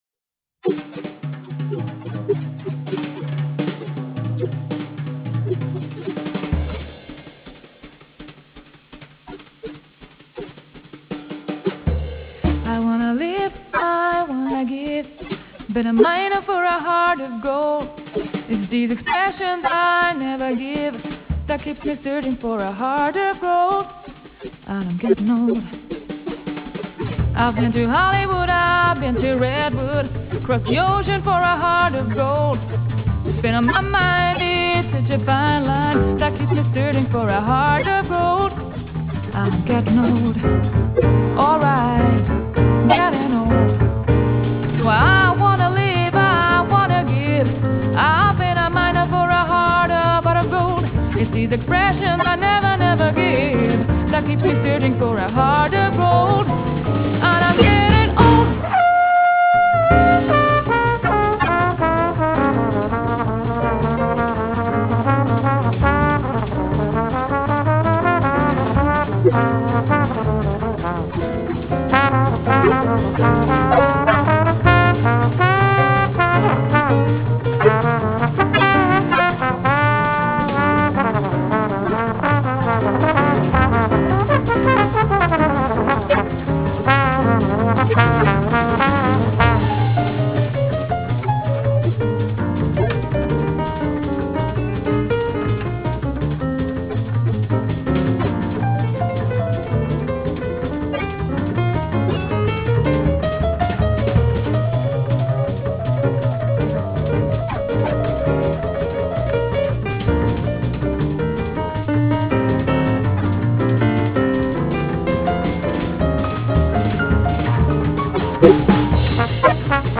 In her voice strength and vulnerability are not antipodes.
piano
drums
bass